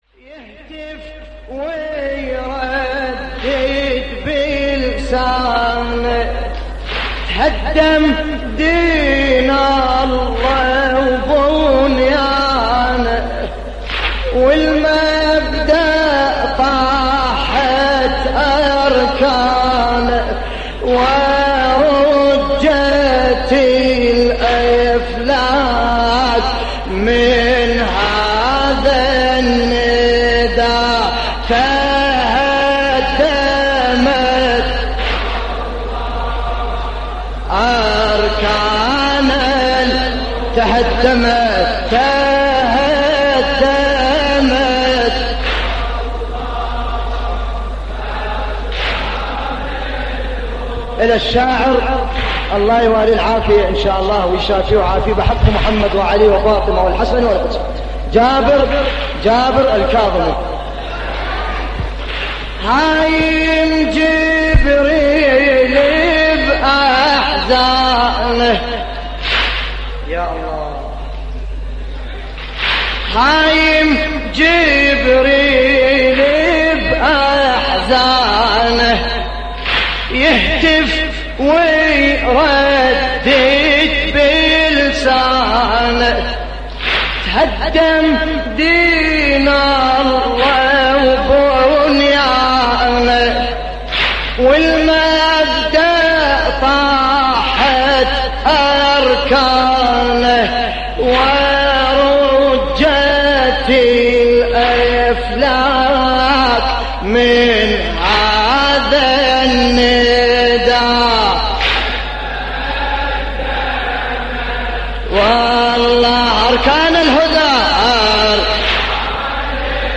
تحميل : تهدمت والله اركان الهدى / الرادود باسم الكربلائي / اللطميات الحسينية / موقع يا حسين